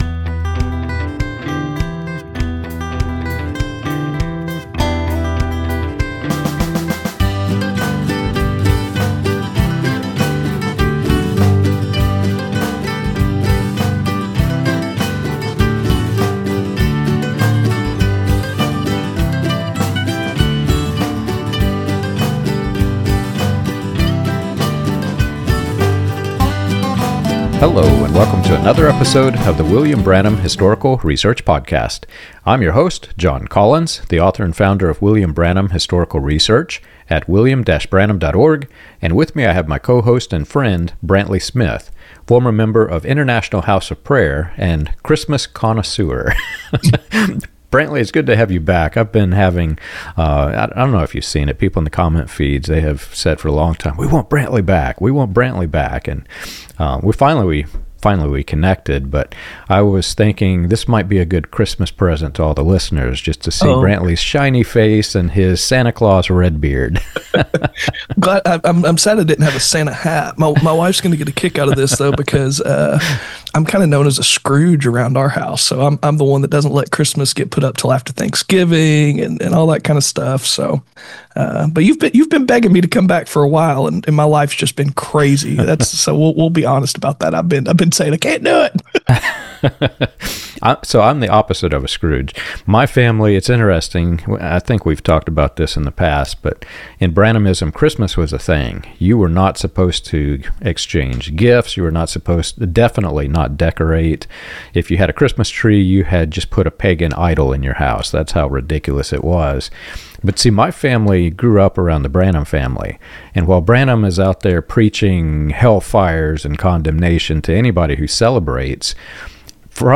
Their candid conversation explores how cult systems redefine holiness by stripping joy from human experience—right down to banning Christmas trees. The episode connects Branham’s mantle claims, the rise of Joel’s Army, and IHOPKC’s culture of overwork and spiritual abuse, tracing how authoritarian leadership transforms faith into fear.